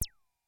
标签： MIDI-速度-83 ASharp5 MIDI音符-82 挡泥板-Chroma-北极星 合成器 单票据 多重采样
声道立体声